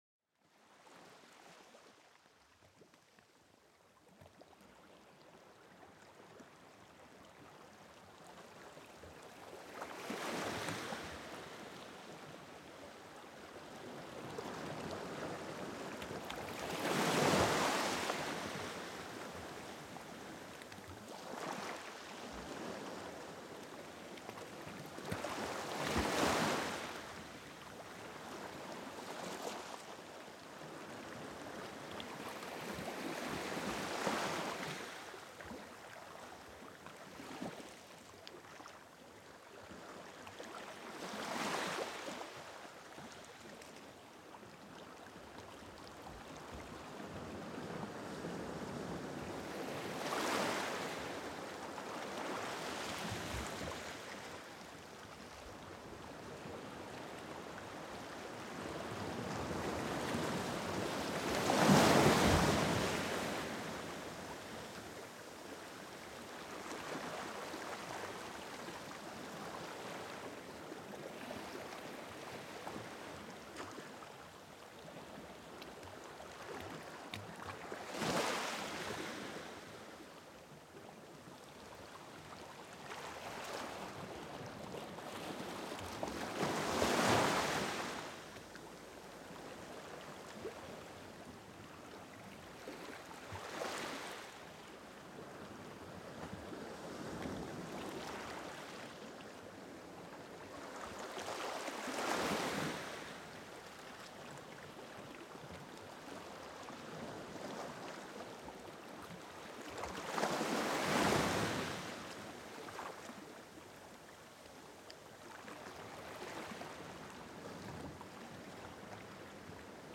Olas calmantes para una relajación total
Deja que el suave sonido de las olas te transporte a un estado profundo de calma para dormir mejor.El ritmo constante de las olas rompiendo en la orilla crea una atmósfera serena y pacífica. Los suaves sonidos del agua te envuelven, ayudándote a desconectar.